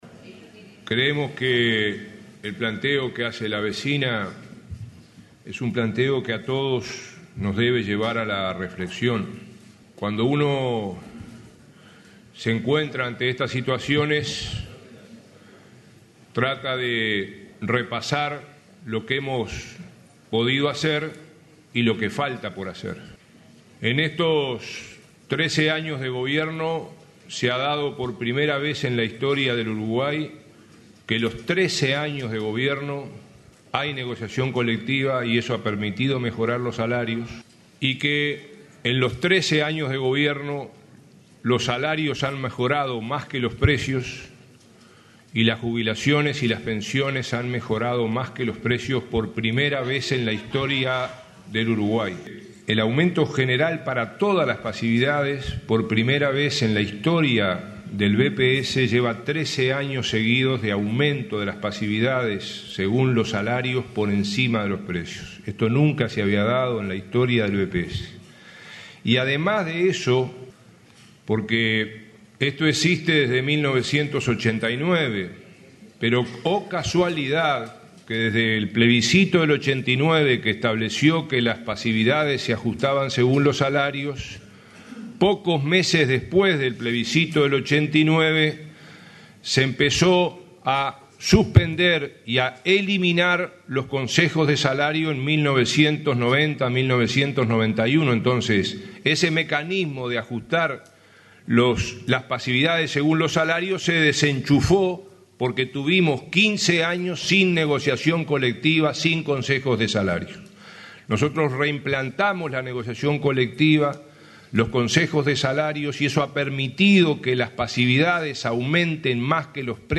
Luego de 15 años sin negociación colectiva, el primer Gobierno del presidente Vázquez reimplantó los consejos de salarios, lo que permitió que, por primera vez en la historia del país, las pasividades aumentaran más que los precios durante 13 años consecutivos, al igual que los salarios. El ministro Ernesto Murro explicó que, además, se dispuso un aumento extra en julio de cada año para las pasividades mínimas.